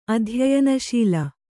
♪ adhyayanaśila